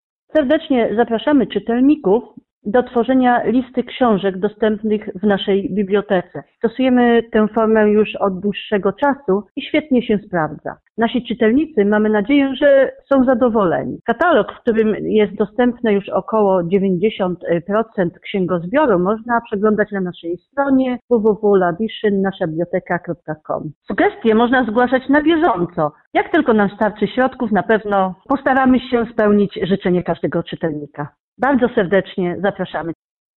Mówiła